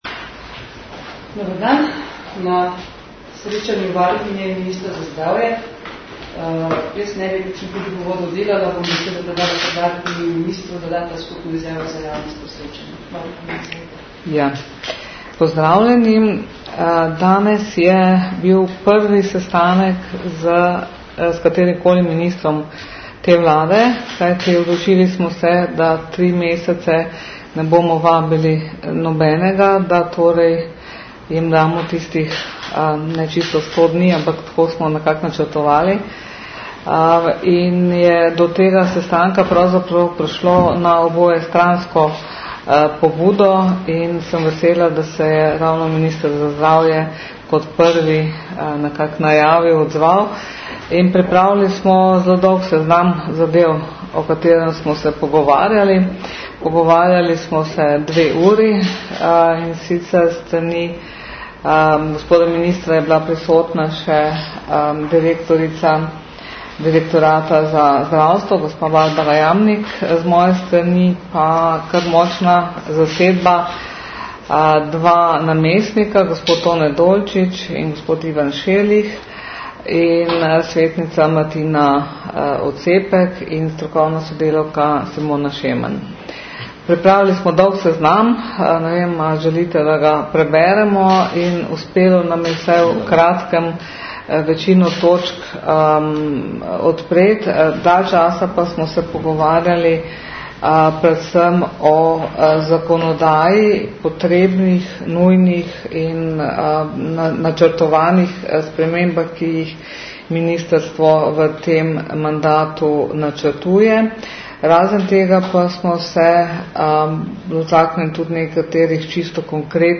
Varuhinja in minister sta ugotovitve srečanja predstavila v skupni izjavi za javnost po srečanju.